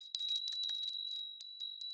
Small Bell
Bell Ding OWI Ring Ringing Shake Small sound effect free sound royalty free Sound Effects